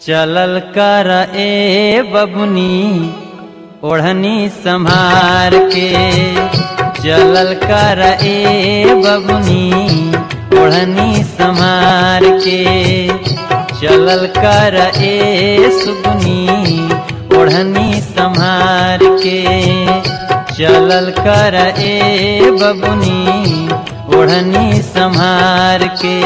Category: Bhojpuri Ringtones